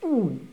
deglutition_01.wav